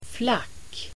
Uttal: [flak:]